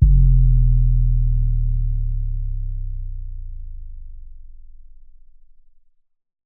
SIZZ!23!808.wav